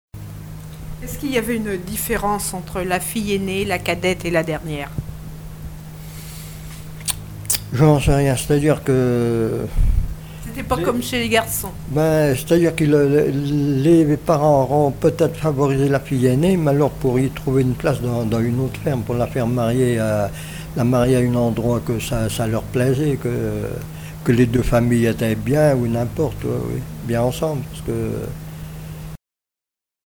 Témoignages de vie